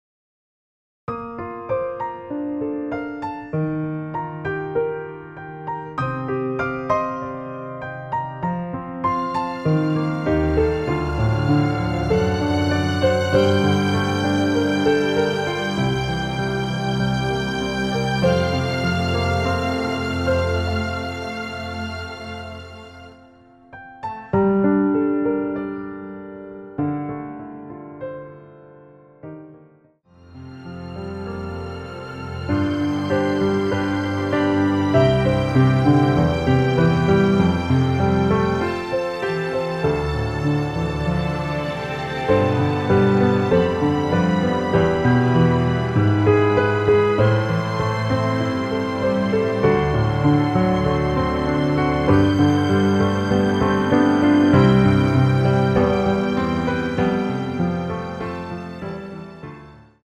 원키에서(-2)내린 MR입니다.
Gm
앞부분30초, 뒷부분30초씩 편집해서 올려 드리고 있습니다.